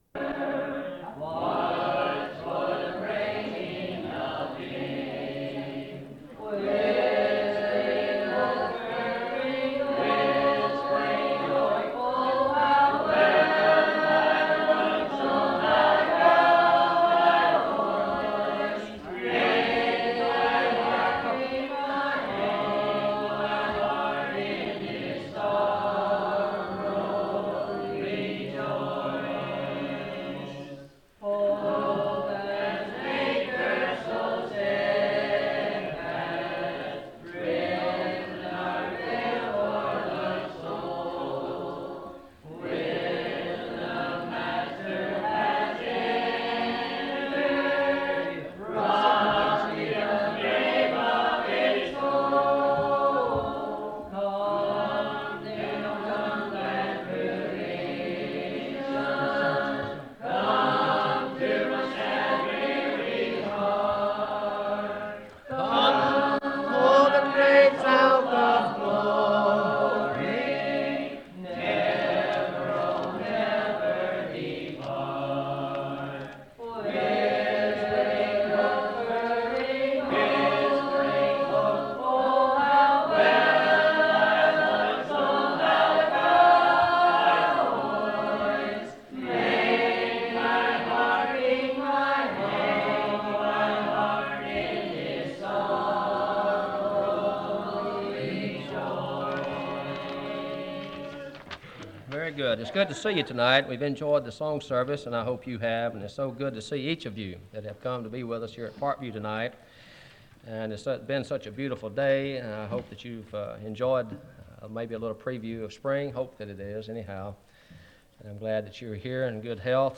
Sermon on the customs of Primitive Baptists and their derivation from scripture